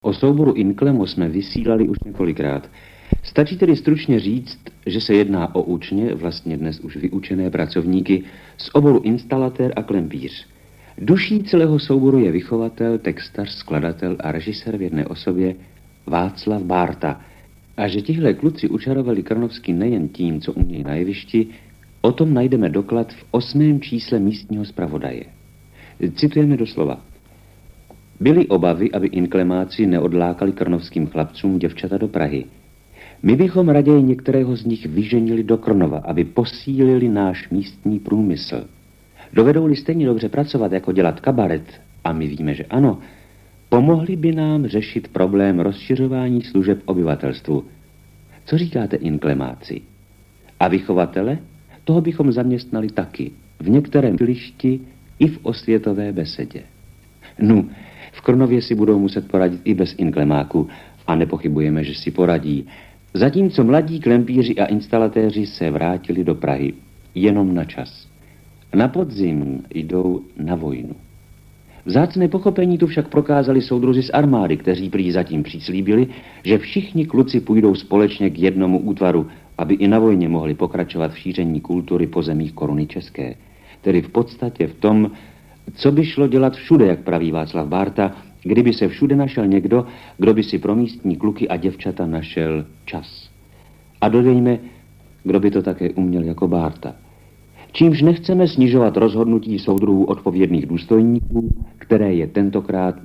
Praha, Inklemo, fragment rozhlasové relace o souboru, 1965
DbČAD > Audio Praha, Inklemo, fragment rozhlasové relace o souboru, 1965 Ke stažení: stáhnout soubor Fragment relace Rozhlasu po drátě, vysílaného z Městského národního výboru v Bruntále, věnovaný VII. ročníku Divadelního máje – Národní přehlídce amatérských divadelních souborů v Krnově. Záznam – fragment vizitky divadelního souboru Inklemo z Prahy pochází z velmi poničené magnetofonové pásky z roku 1965.